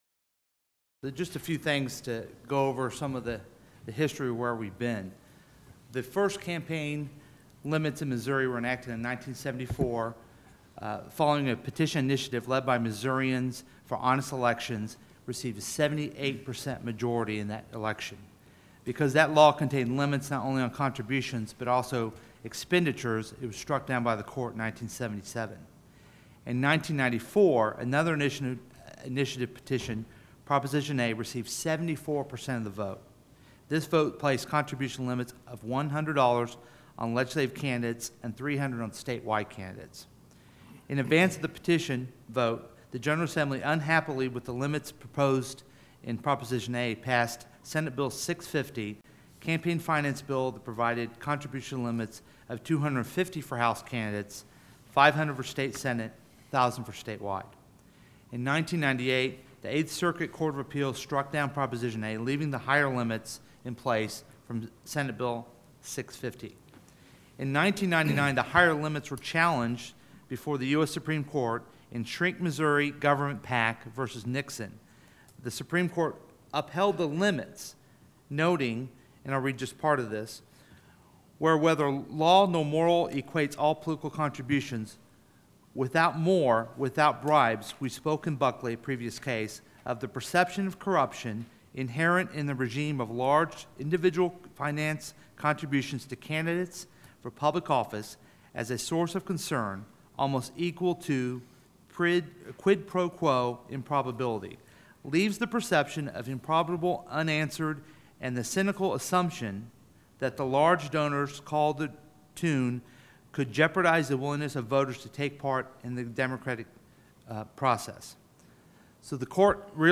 Senator LeVota presents Senate Bill 96 to the Missouri Senate Rules, Joint Rules, Resolutions and Ethics Committee. His proposal would establish campaign contribution limits.